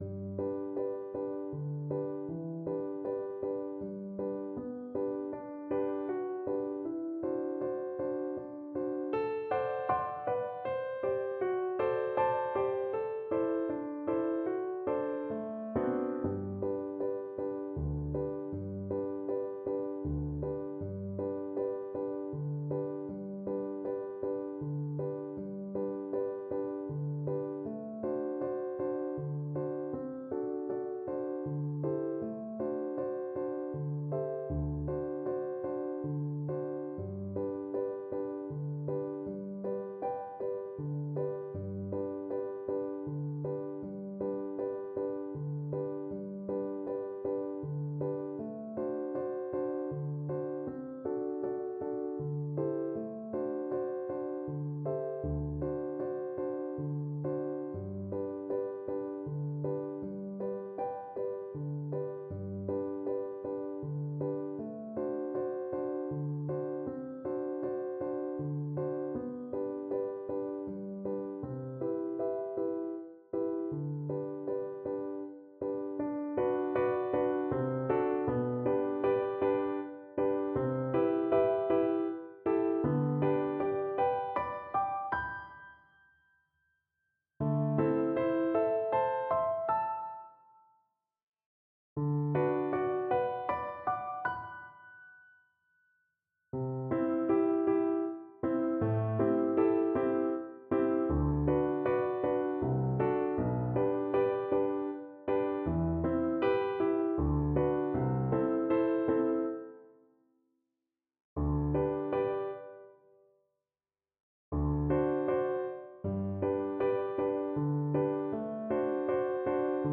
Allegretto =116 Allegretto =120
3/4 (View more 3/4 Music)
Classical (View more Classical Cello Music)